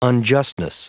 Prononciation du mot unjustness en anglais (fichier audio)
Prononciation du mot : unjustness